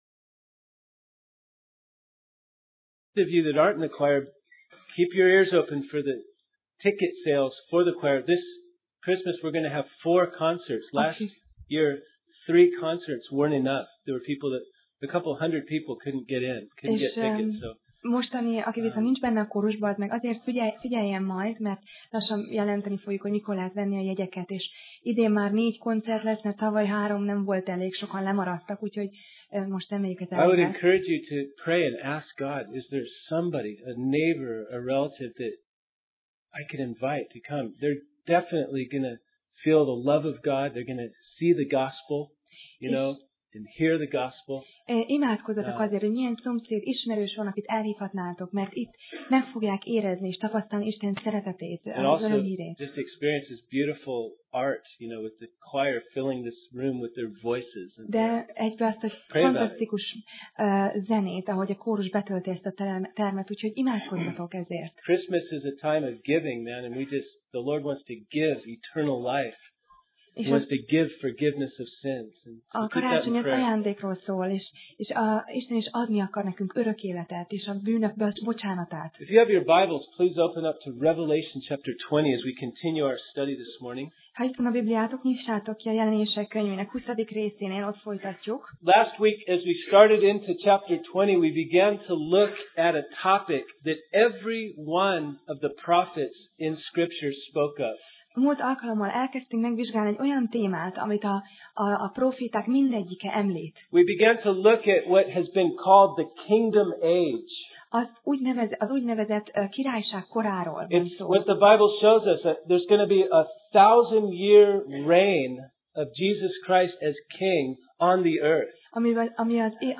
Passage: Jelenések (Revelation) 20:7-15 Alkalom: Vasárnap Reggel